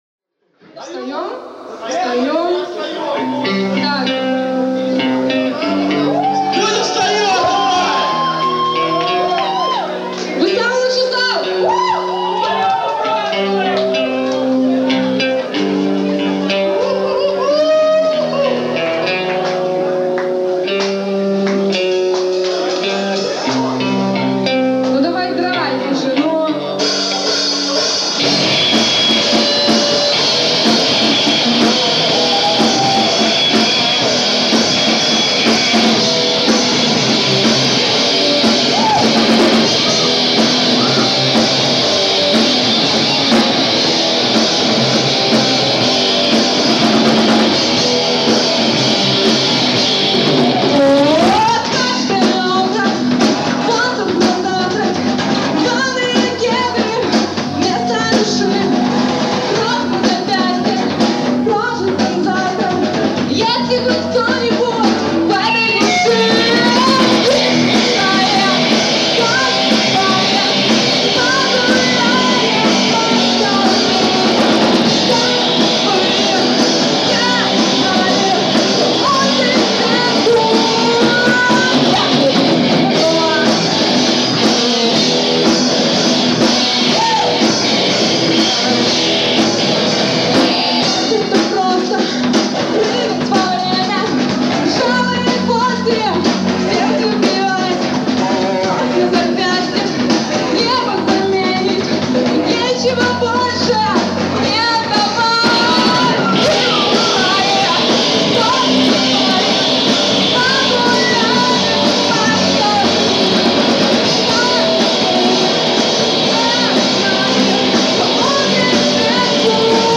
Live in "Добрыя Мыслi" (31.03.06)